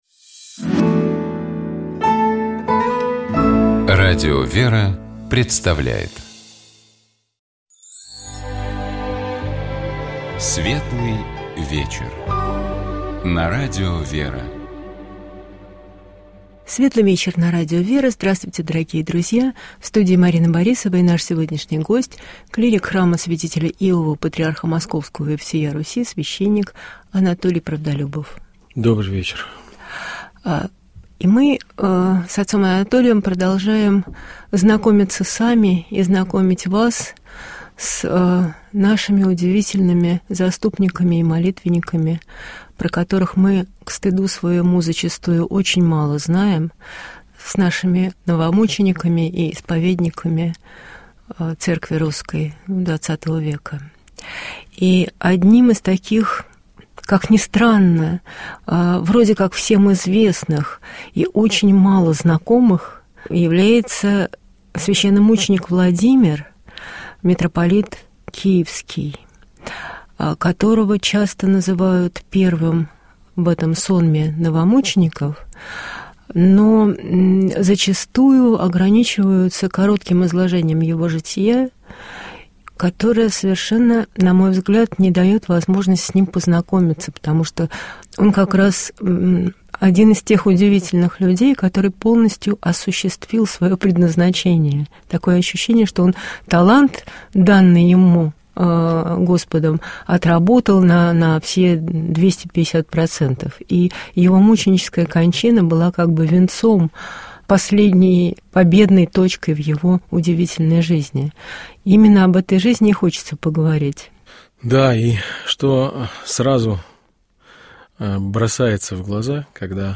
Мы говорили о христианском понимании любви, о том, может ли она быть через силу, а также о том, как современному человеку можно учиться той любви, к которой призывает нас Господь. Разговор шел о том, как разделять любовь и эмоции, и чем сопереживание отличается от жалости.